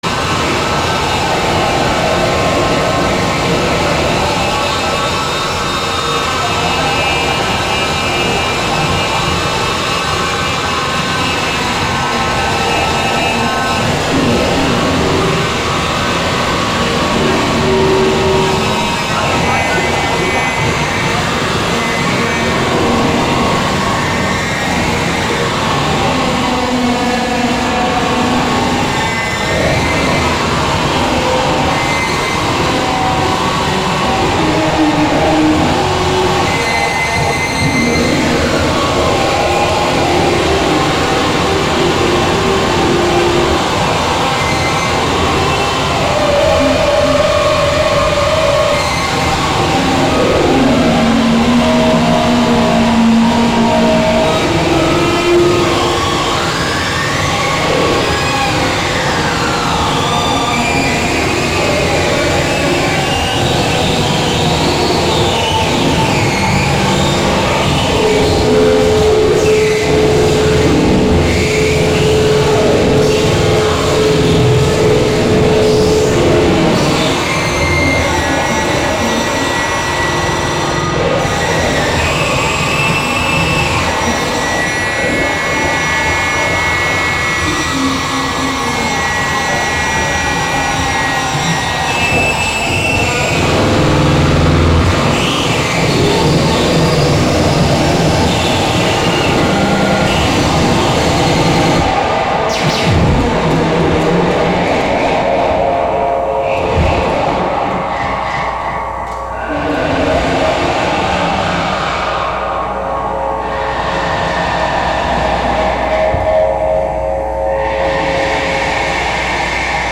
Wave Farm | Live from BuddyFest: Buddyfest